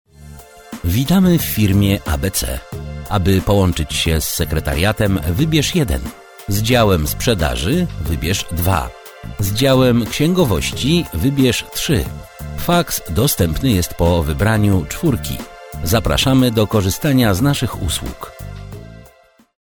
Male 50 lat +
Nagranie lektorskie